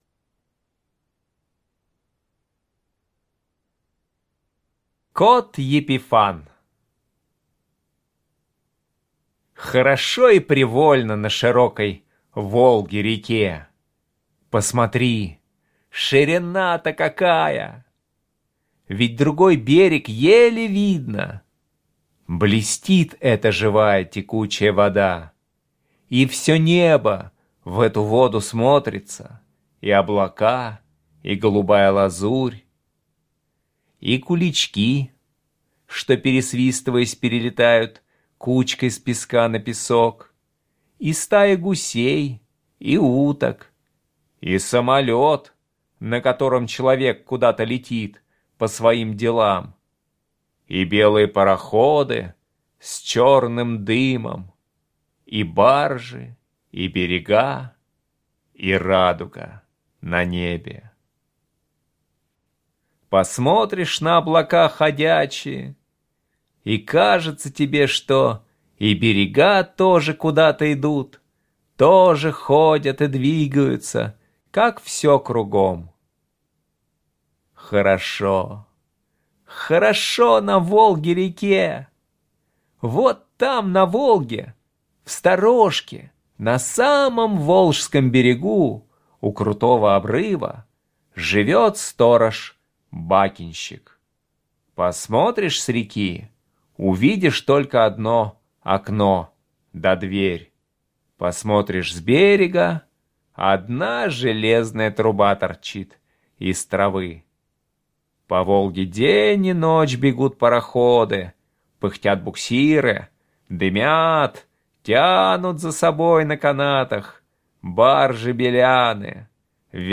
Слушайте Кот Епифан - аудио рассказ Чарушина Е.И. Однажды к бакенщику на Волге пришел пушистый кот и остался жить. Он ходил удить рыбу с бакенщиком.